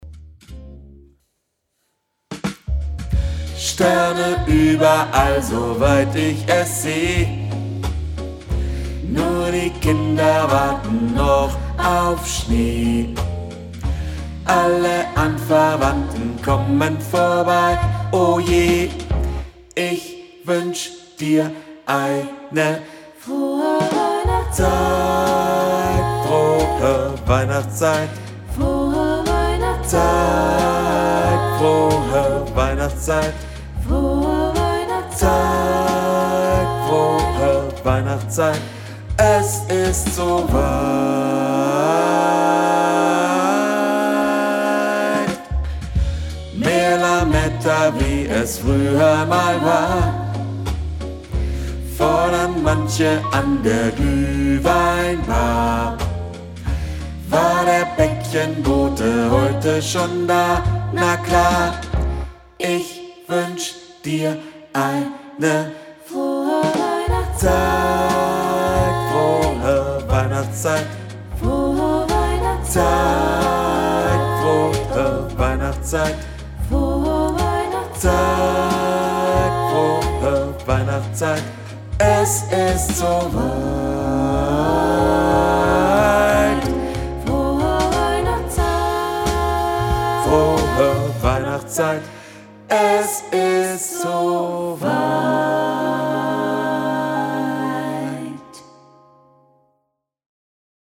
Übungsaufnahme Bass
Frohe_Weihnachtszeit  - Bass.mp3